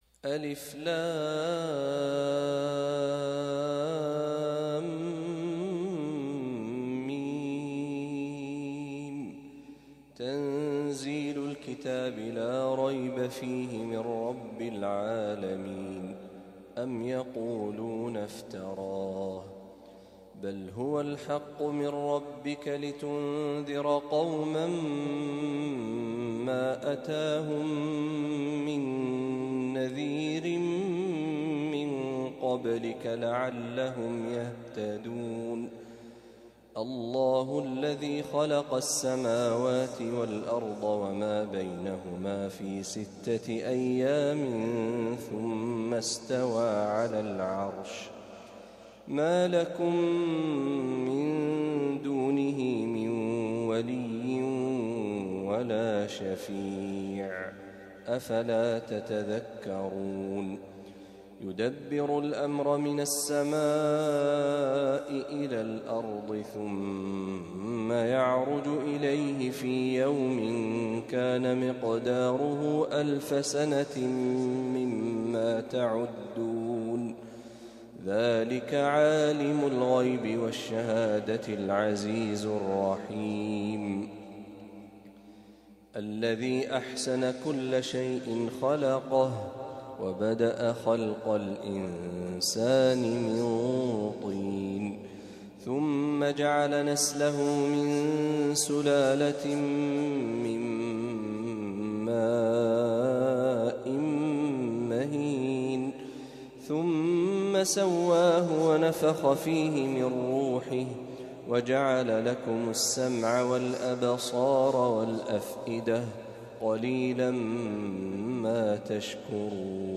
تلاوة لسورتي السجدة والإنسان | فجر الجمعة ٦ محرم ١٤٤٦هـ > 1446هـ > تلاوات الشيخ محمد برهجي > المزيد - تلاوات الحرمين